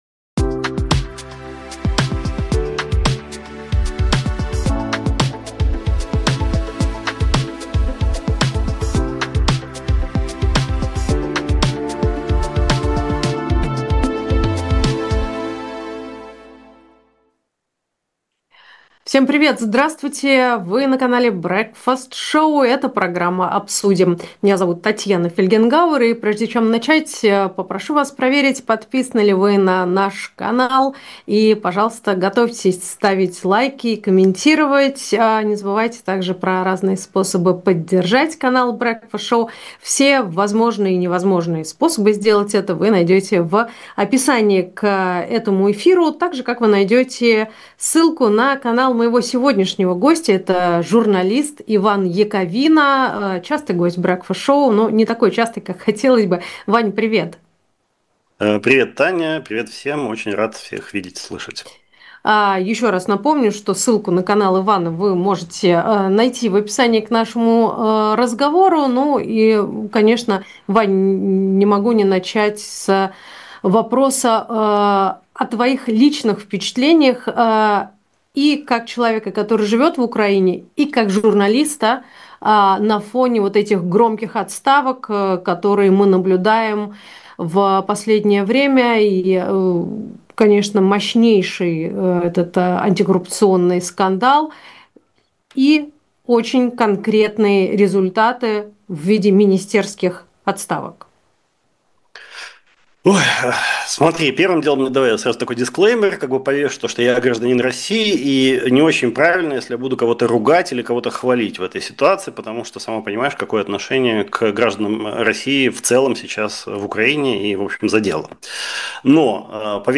Эфир ведёт Татьяна Фельгенгауэр